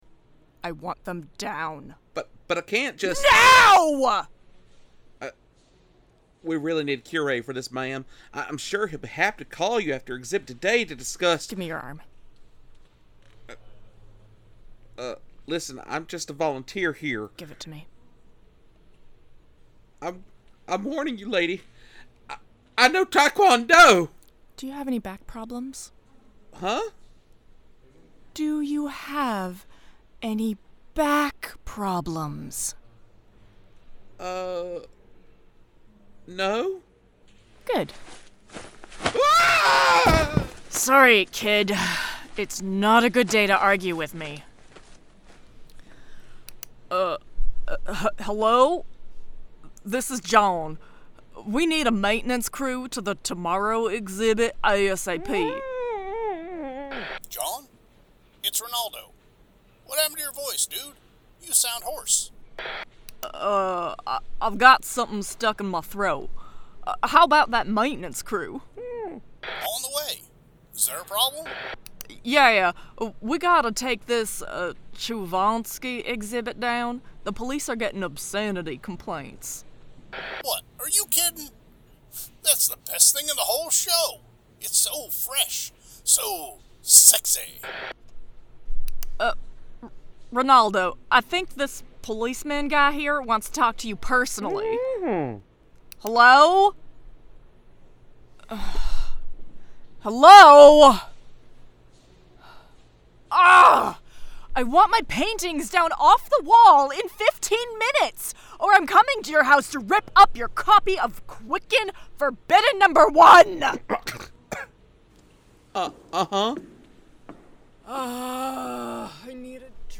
Strangers In Paradise – The Audio Drama – Book 7 – Episode 10 – Two True Freaks